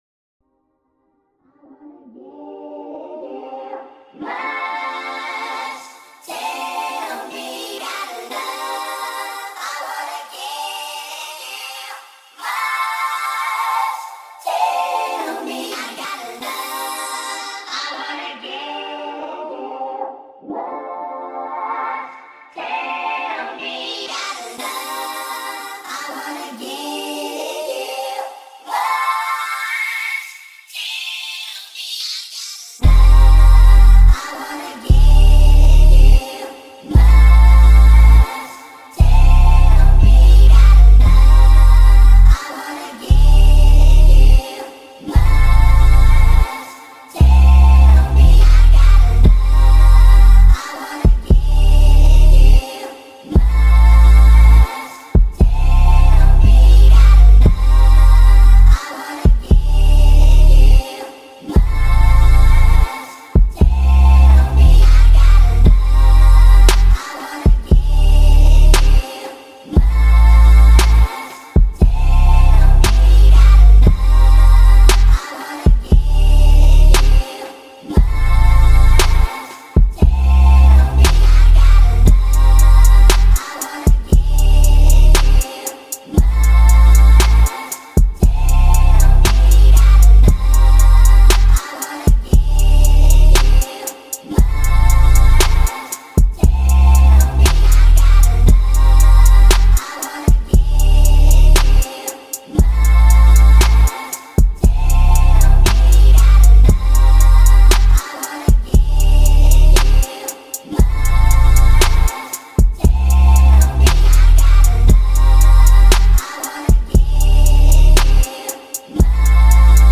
BPM: 117.
Music / Rap
lofi beat chillhop rap trap instrumental hip hop soulful jazzy
sample old school rhythm and blues